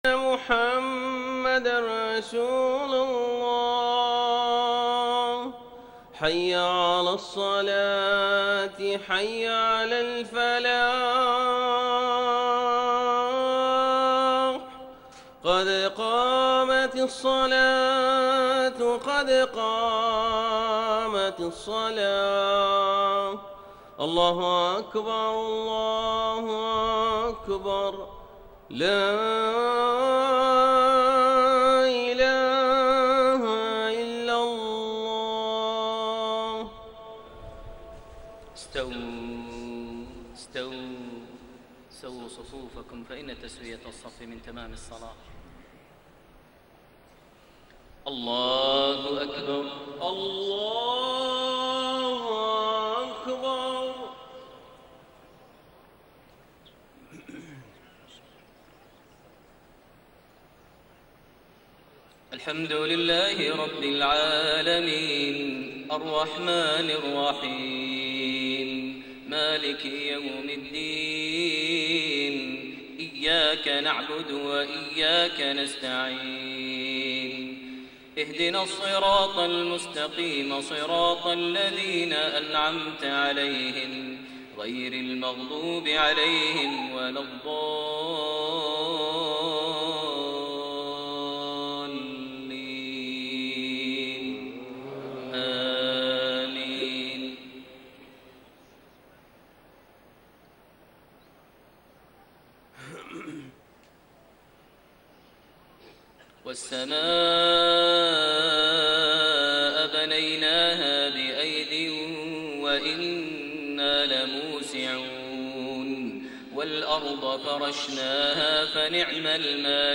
صلاة المغرب 14 ذو القعدة 1432هـ خواتيم سورة الذاريات 47-60 > 1432 هـ > الفروض - تلاوات ماهر المعيقلي